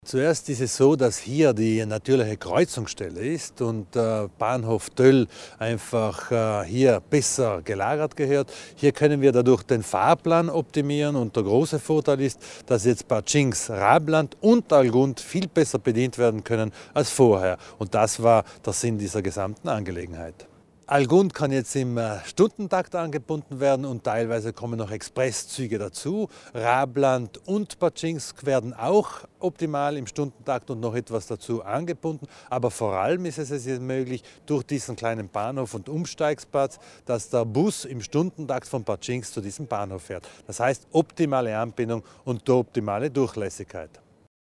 Landesrat Thomas Widmann zu den Vorzügen des neuen Bahnhofs Töll